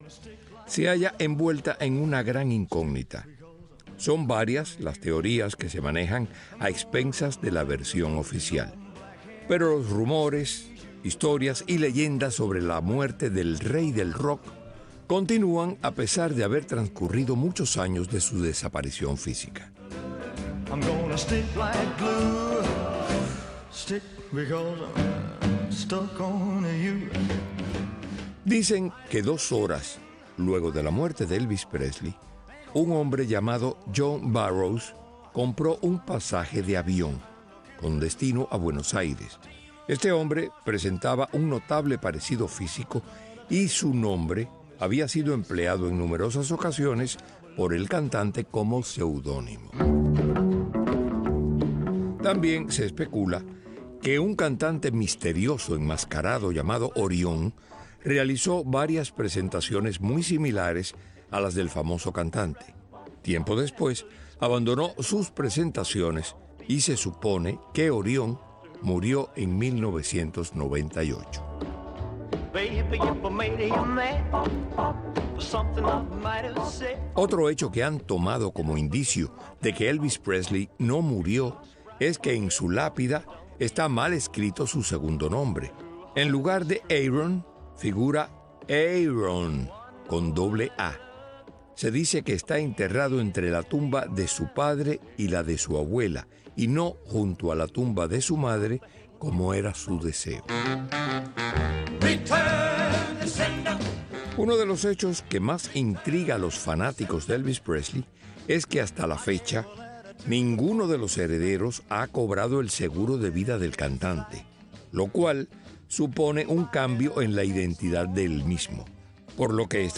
Cuba en las Redes es un espacio radial que recoge el acontecer de la isla expuesto en las redes sociales por sus ciudadanos.